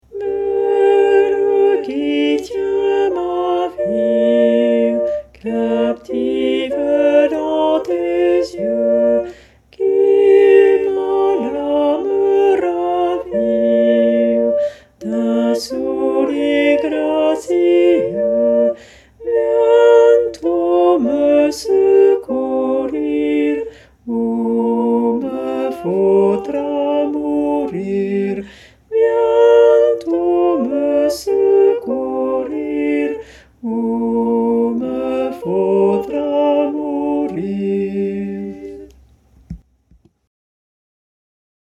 VeRSION CHANTEE
Pavane-Basses.mp3